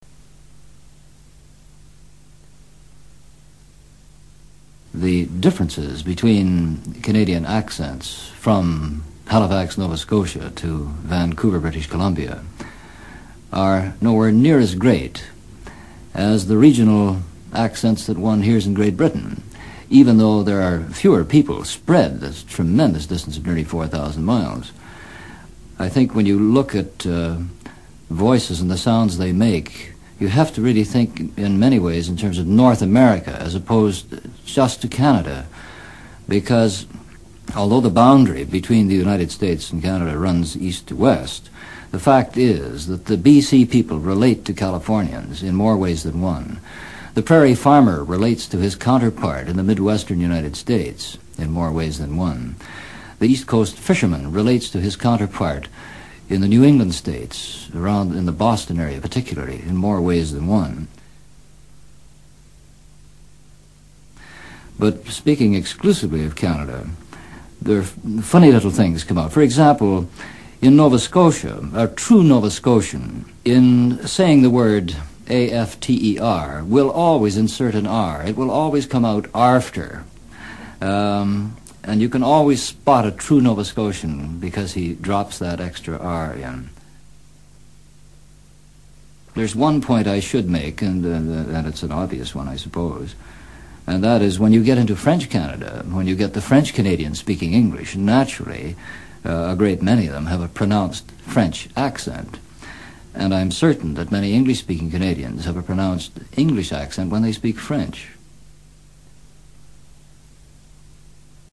A Canadian Accent
A technician from Quebec speaks.
Sin embargo, existe un rasgo que distingue el acento canadiense del estadounidense: la pronunciación de las vocales ou en palabras como out, mouth o house.
CANADA-Quebec.mp3